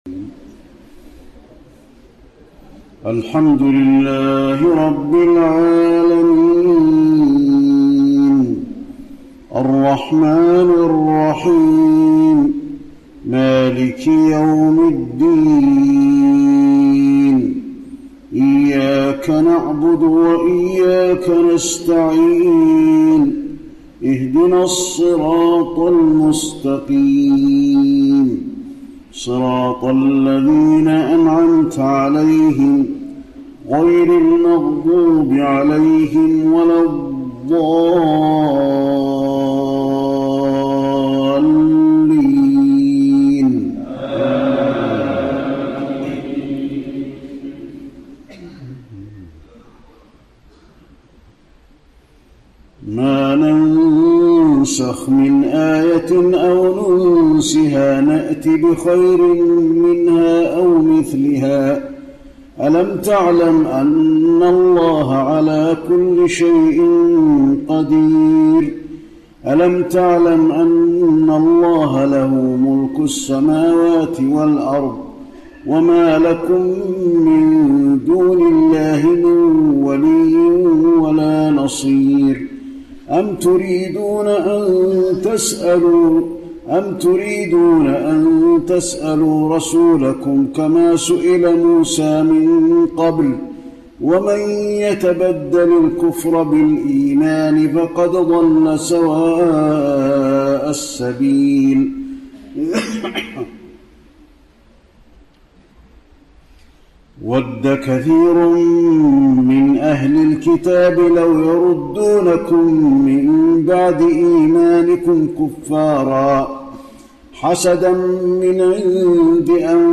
تهجد ليلة 21 رمضان 1431هـ من سورة البقرة (106-176) Tahajjud 21 st night Ramadan 1431H from Surah Al-Baqara > تراويح الحرم النبوي عام 1431 🕌 > التراويح - تلاوات الحرمين